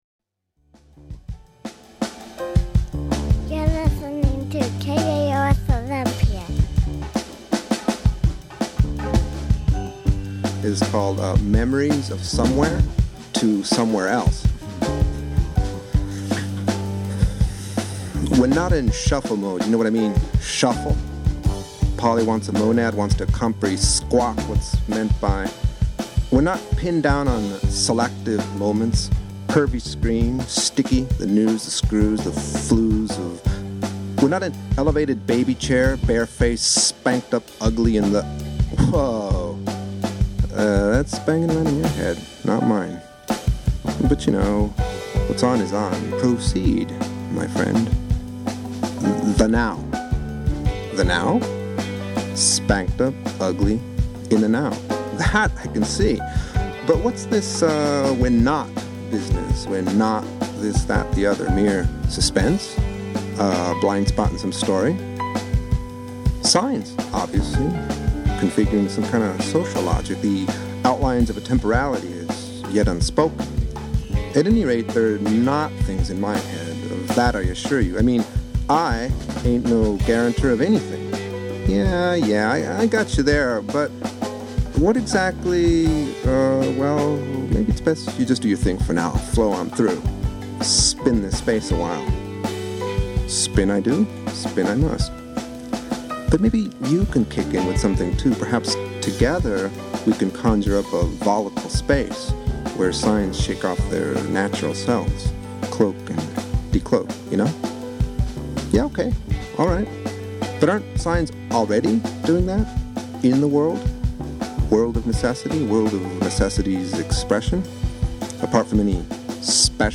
“A lil something different” KAOS radio show, Olympia, WA